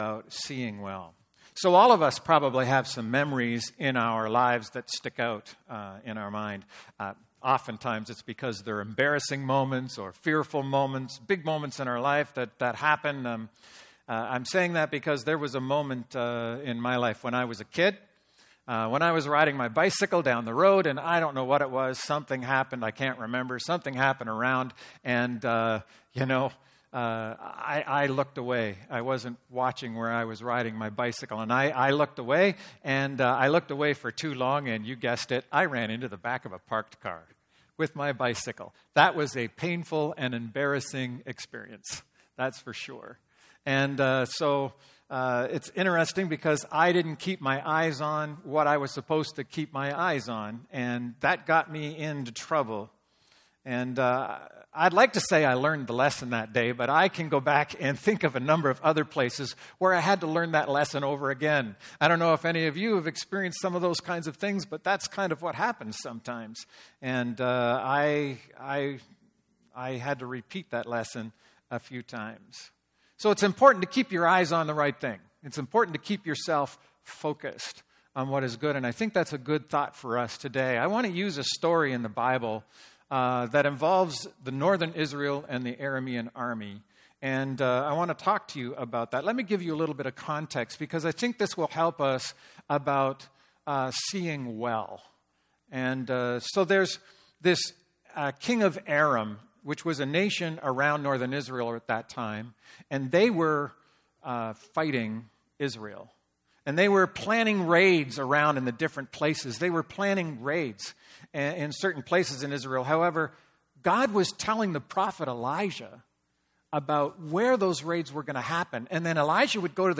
Sermons | Kingston Gospel Temple